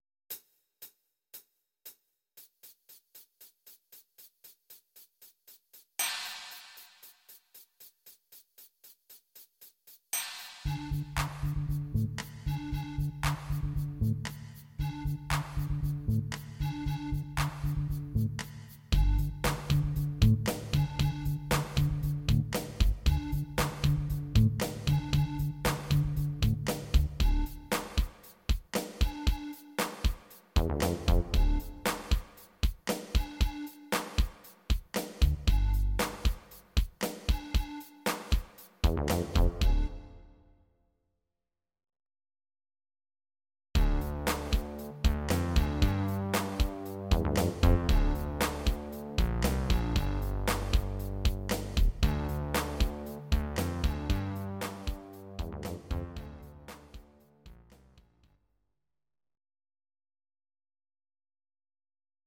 Audio Recordings based on Midi-files
Pop, Musical/Film/TV, 1990s